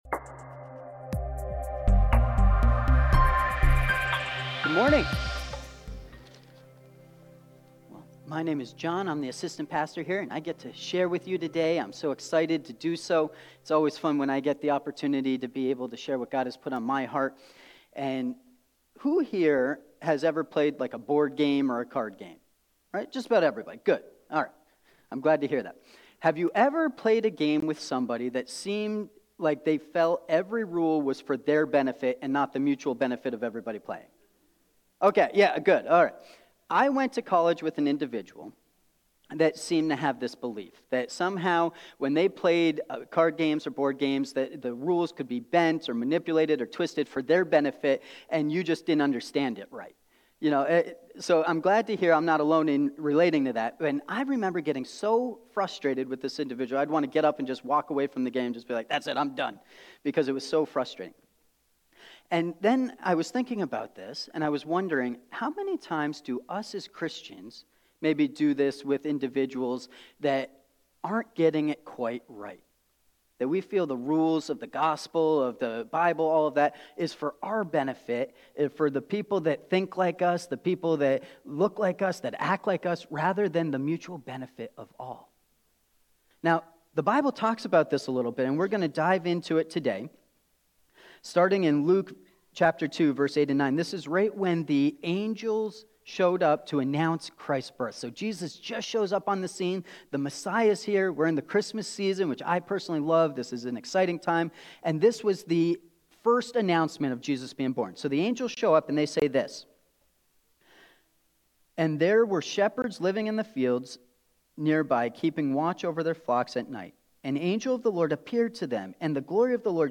For-All-People-Sermon.mp3